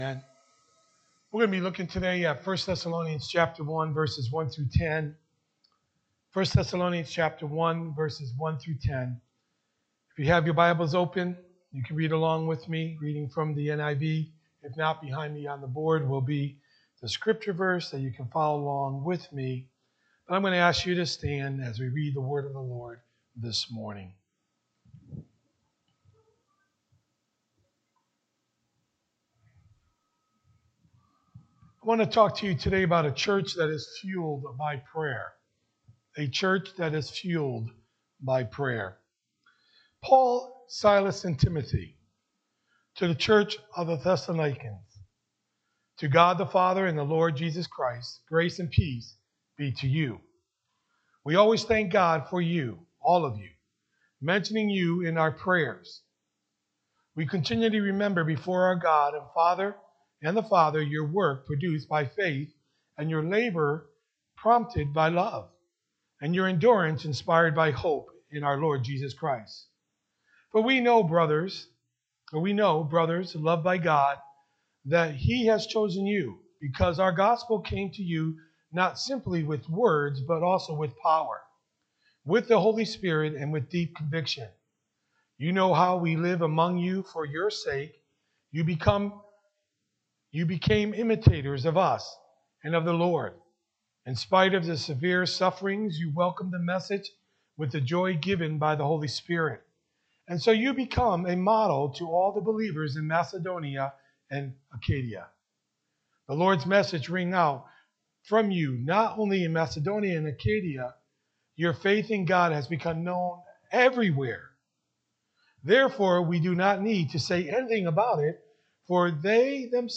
Sermons | Oneonta Assembly of God